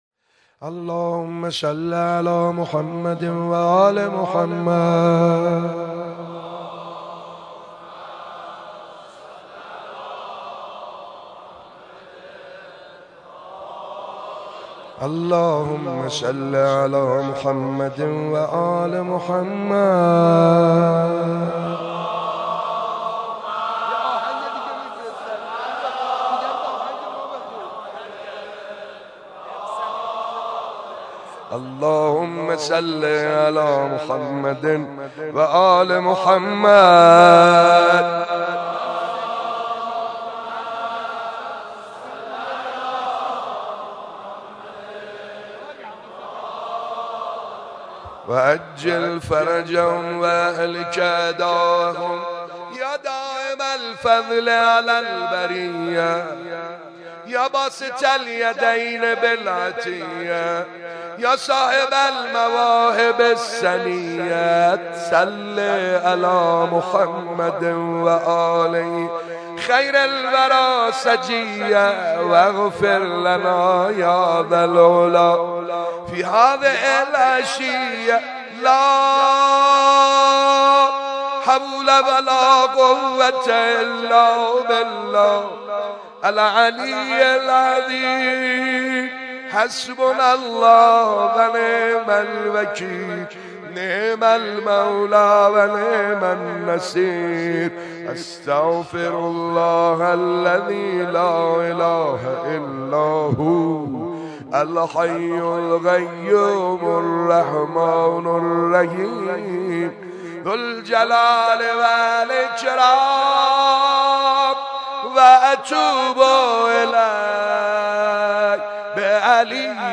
روضه حاج سعید حدادیان برای امیرالمومنین(ع) - تسنیم
خبرگزاری تسنیم: فایل صوتی روضه حاج سعید حدادیان برای امیرالمومنین(ع) منتشر می شود.